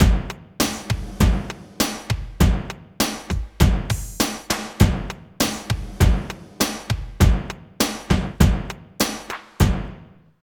12 DRUM LP-L.wav